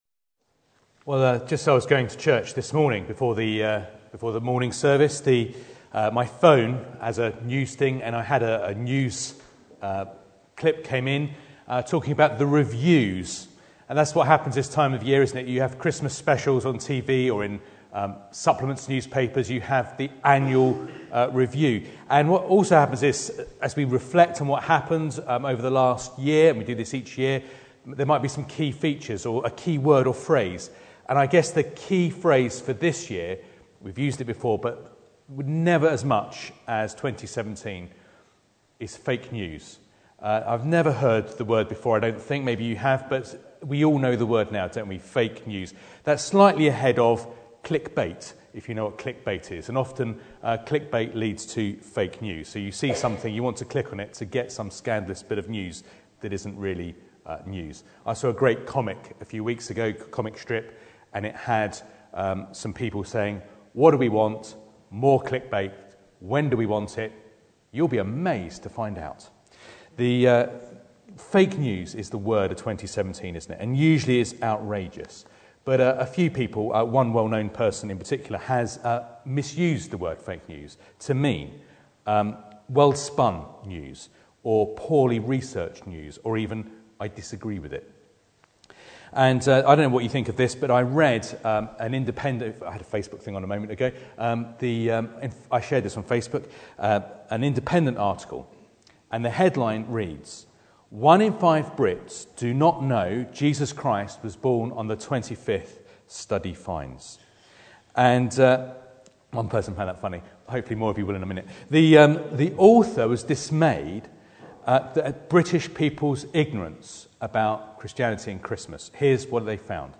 Carol Service
Advent Service Type: Sunday Evening Preacher